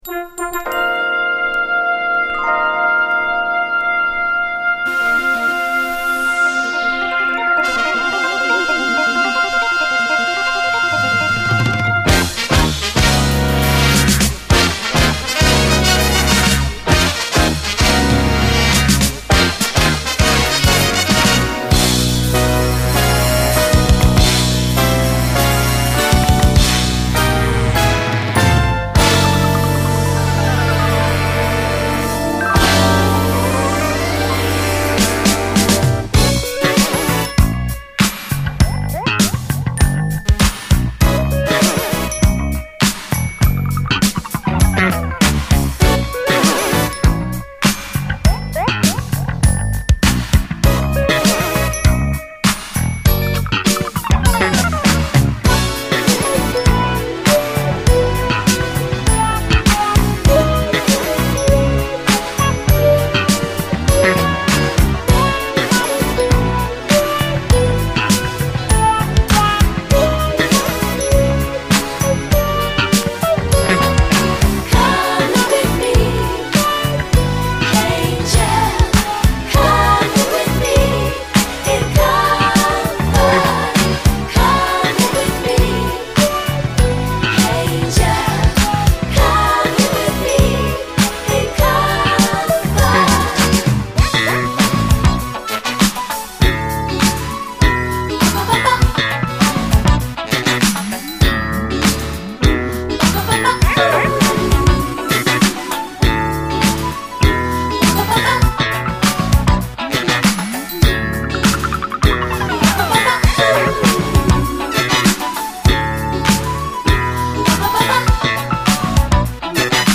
SOUL, 70's～ SOUL
異常値のカッコよさのシンセ・ブギー・モダン・ソウル・アレンジと展開は悶絶必至！
華々しいブラス・フレーズ＆パパパ・コーラスの後半の展開も最高で